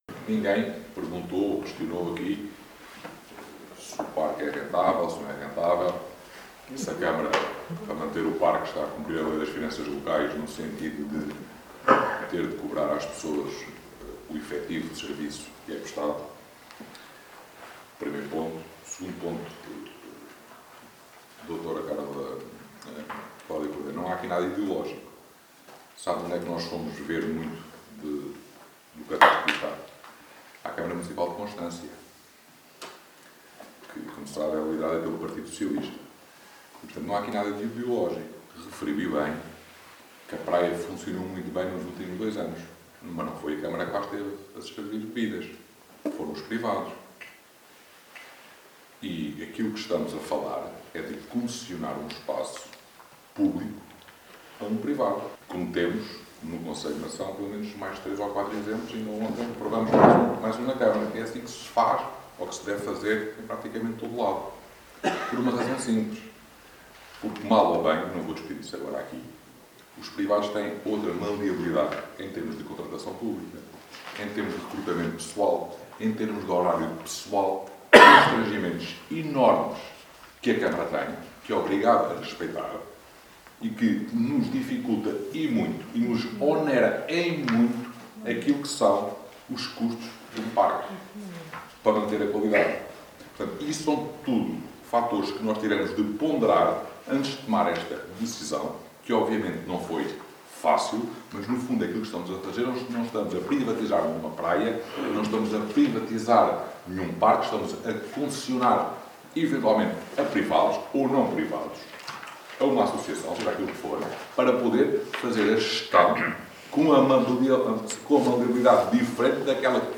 ÁUDIO | Vasco Estrela, presidente da Câmara Municipal de Mação